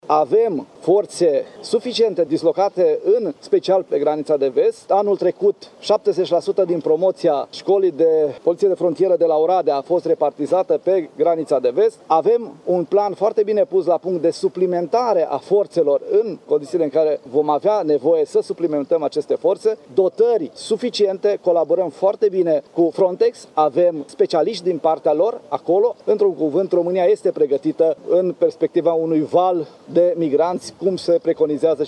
România are capacitate de a primi migranți din Afganistan, dar condiționat, spune ministrul de Interne, Lucian Bode.